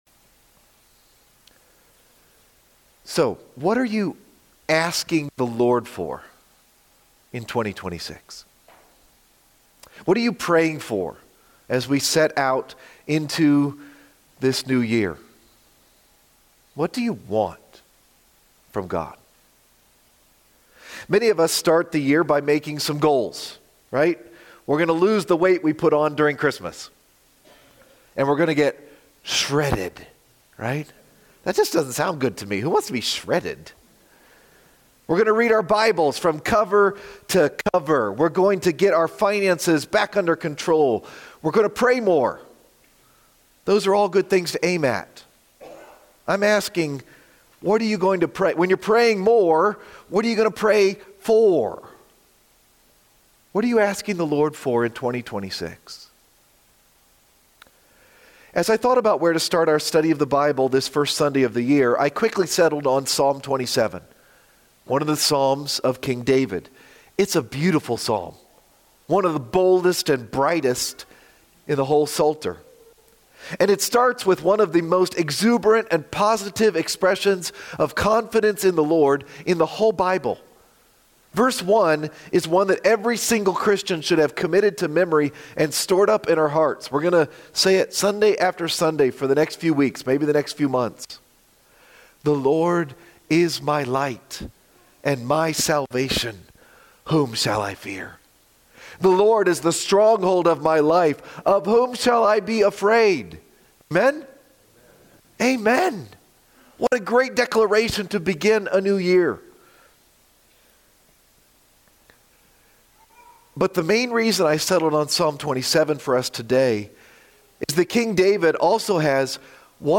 One Thing I Ask :: January 4, 2026 - Lanse Free Church :: Lanse, PA